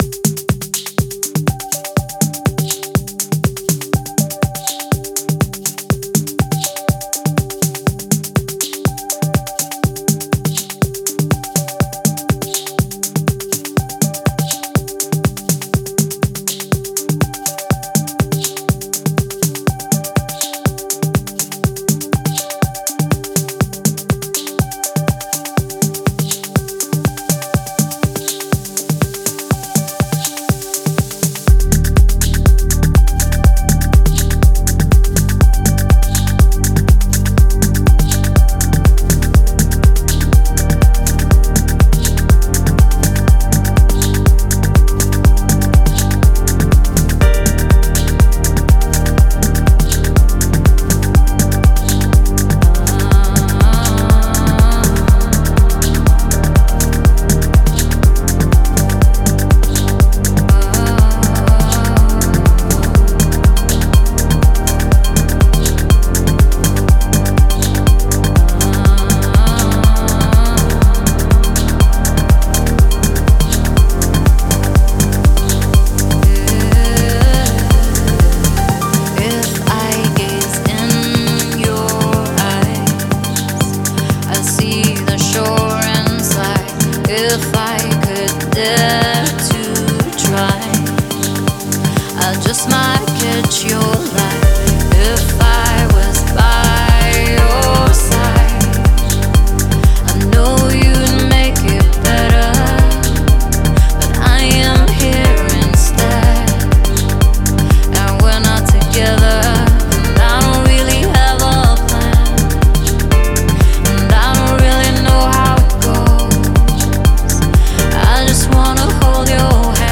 • Жанр: House, Techno